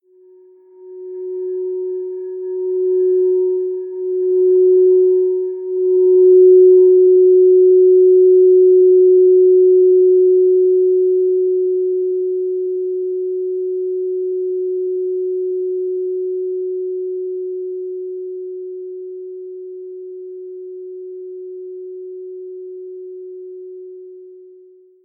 11" : F Note : 440Hz : Perfect Pitch : Heart Chakra : Gratitude | Fae, Flower & Stone